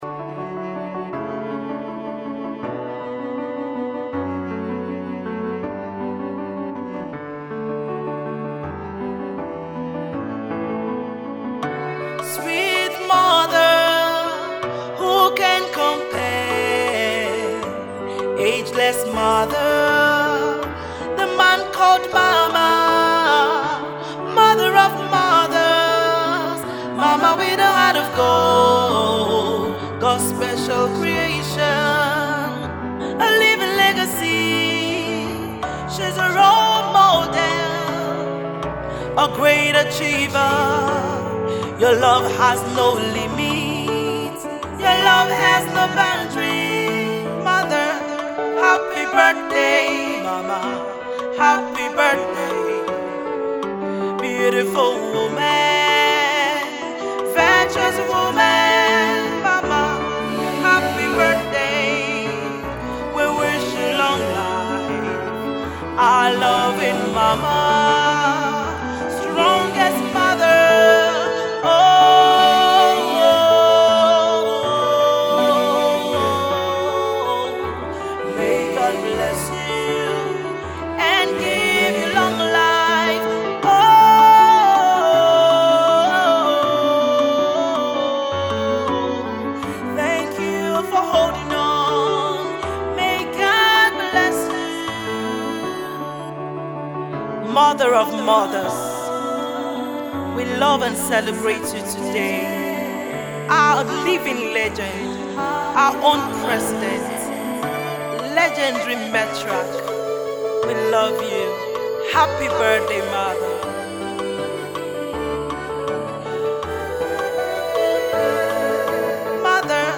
gospel music
soul-lifting song